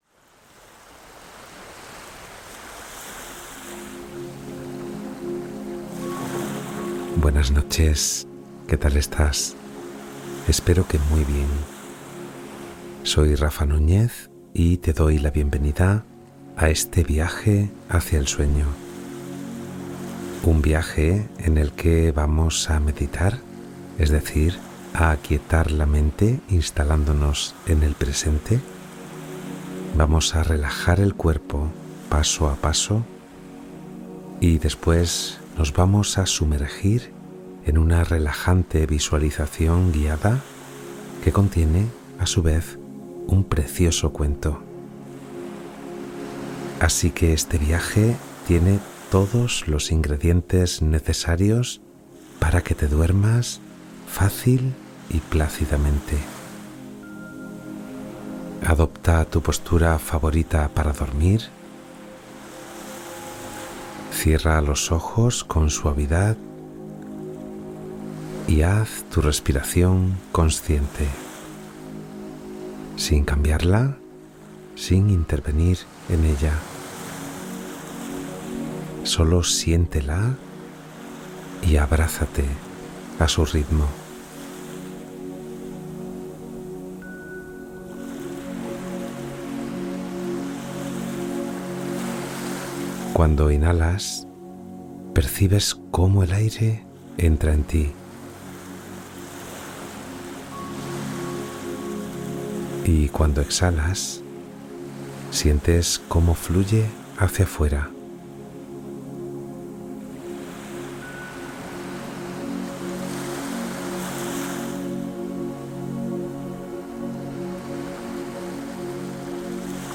Meditación relajante con cuento para dormir profundamente y restaurar tu ser